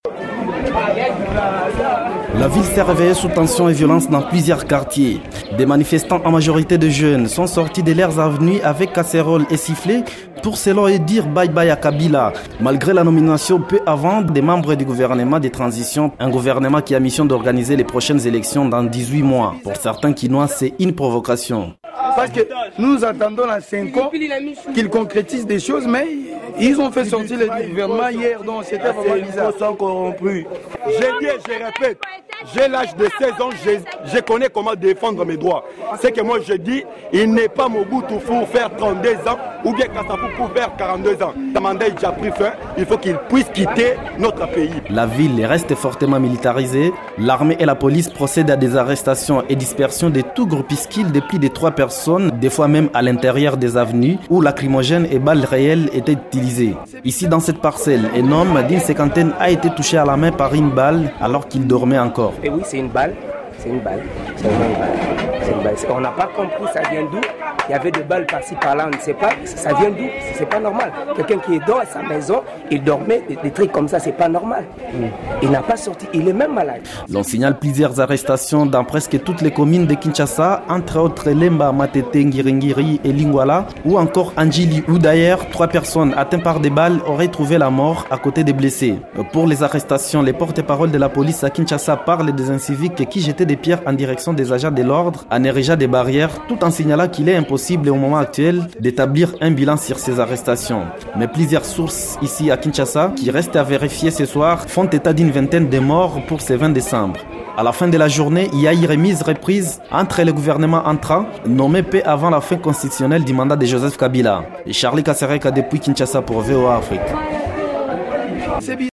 André Kimbuta Yango au micro de Top Congo FM notre partenaire à Kinshasa